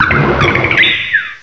cry_not_sigilyph.aif